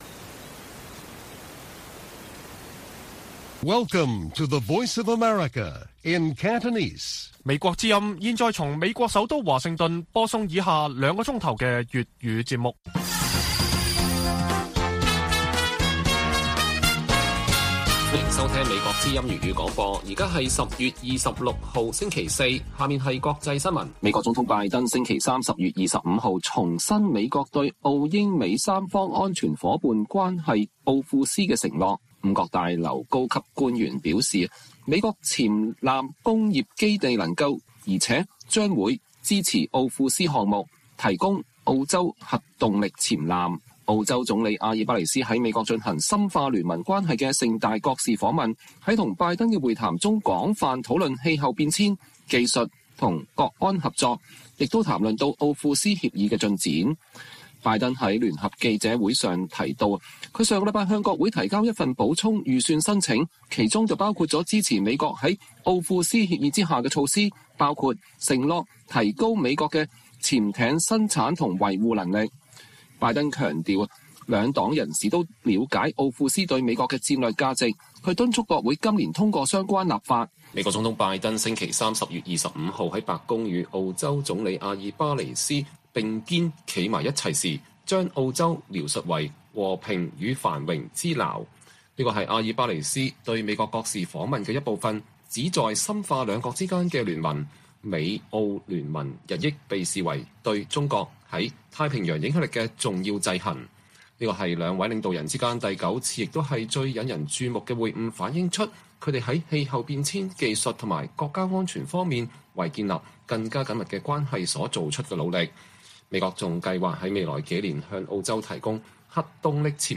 粵語新聞 晚上9-10點 : 台灣連線成員約翰遜當選美國眾議院議長，曾提多項涉華議案